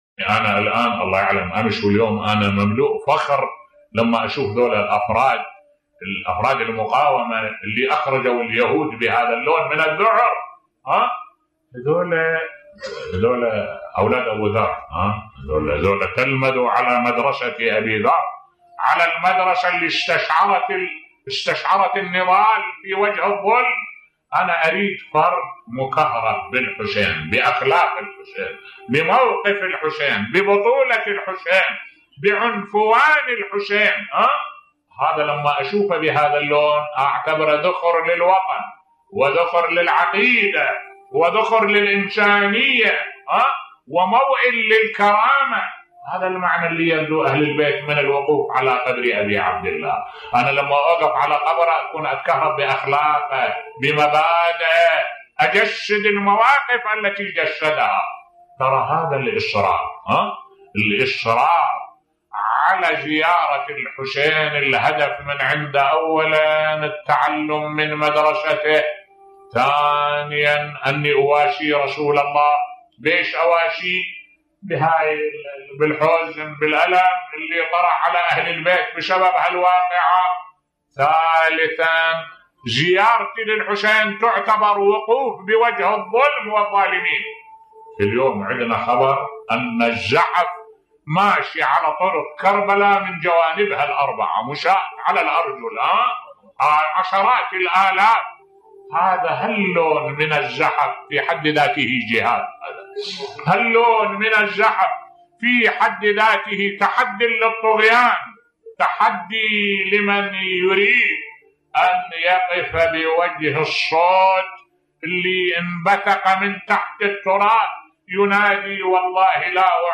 ملف صوتی لماذا نزور الإمام الحسين (ع) بصوت الشيخ الدكتور أحمد الوائلي